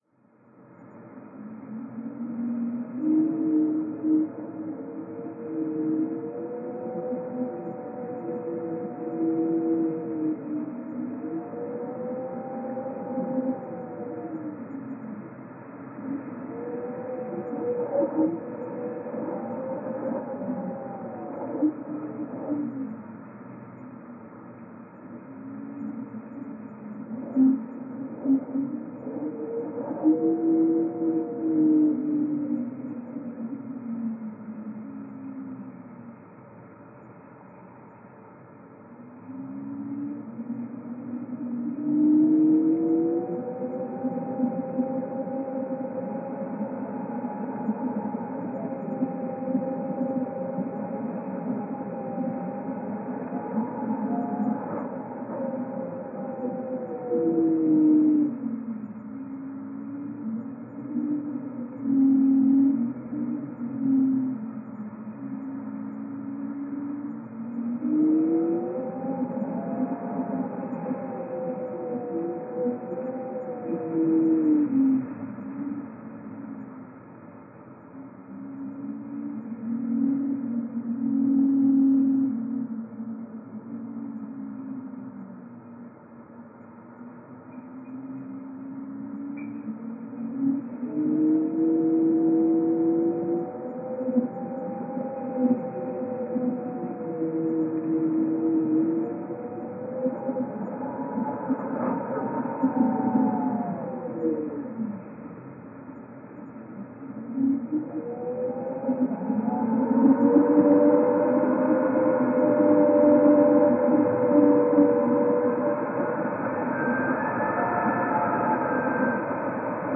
描述：风暴声通过邮箱
Tag: 风暴 天气 氛围